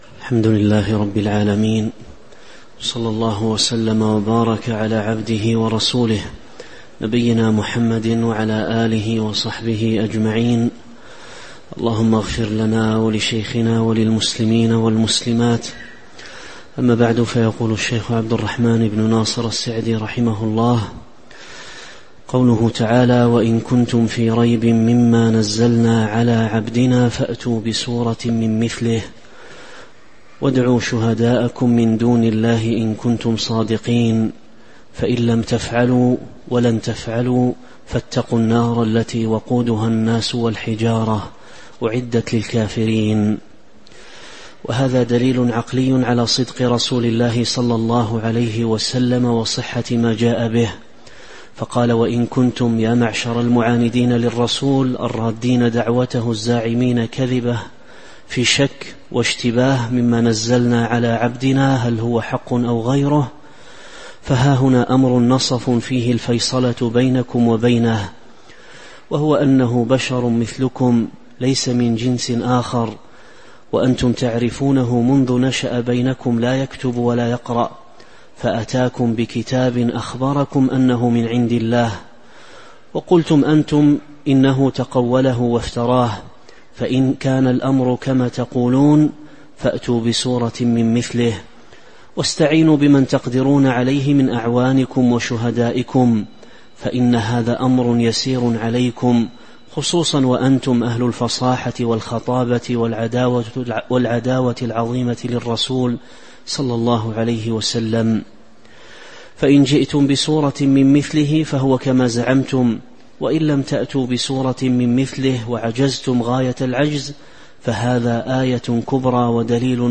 تاريخ النشر ٣٠ ربيع الأول ١٤٤٦ هـ المكان: المسجد النبوي الشيخ